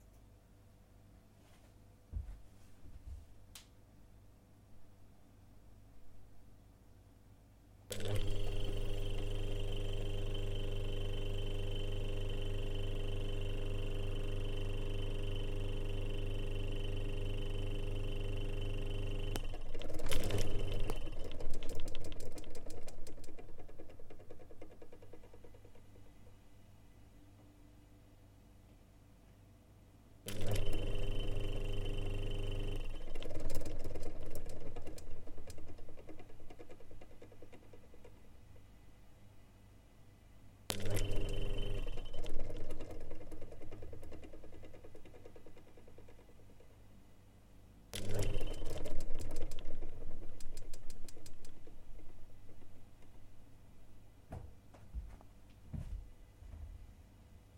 冰箱 " Oldestfridge(2)
描述：旧苏联冰箱。
Tag: 厨房 冰箱 电机 房子记录 冰箱 国内 发动机